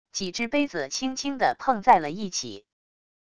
几只杯子轻轻的碰在了一起wav音频